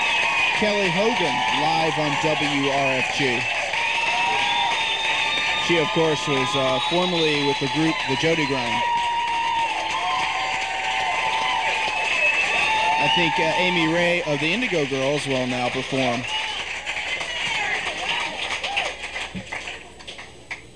songwriter showcase
(radio broadcast)
10. radio announcer (0:20)